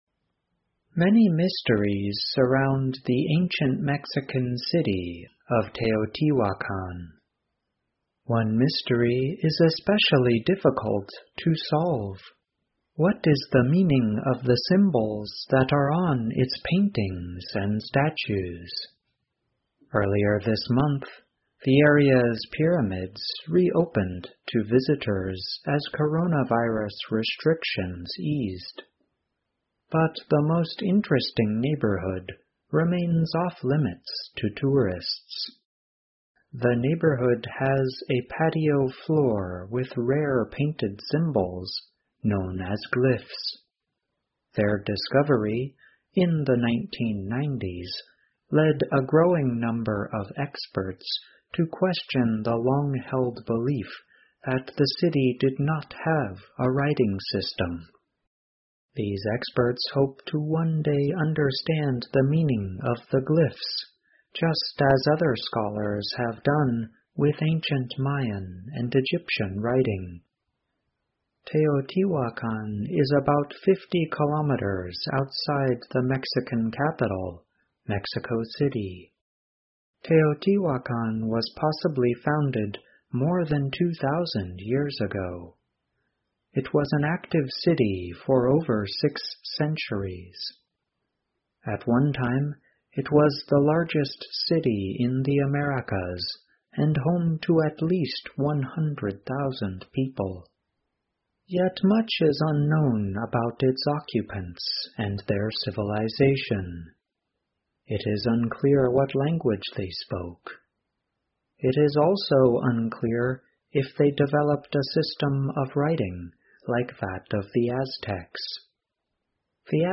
VOA慢速英语--专家在墨西哥古城研究古文字 听力文件下载—在线英语听力室